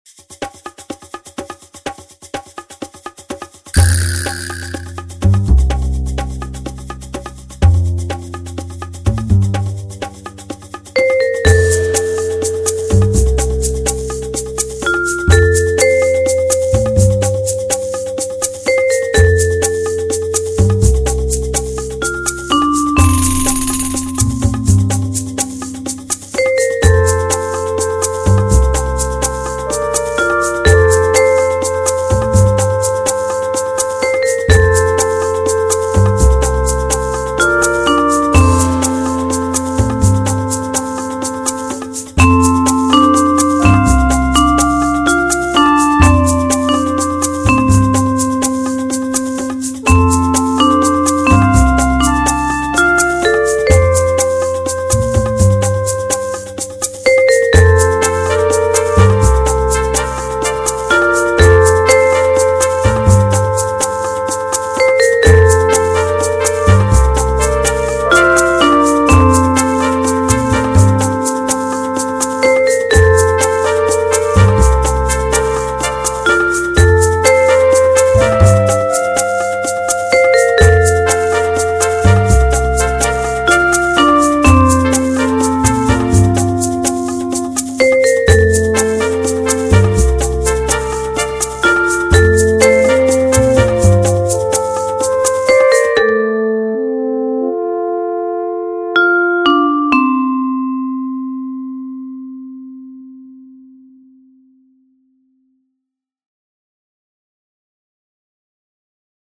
By writing an upbeat dance tune with an international sound, I've done my best to capture the spirit of the My Hero Project - friendship and acceptance without borders.